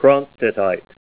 Help on Name Pronunciation: Name Pronunciation: Cronstedtite + Pronunciation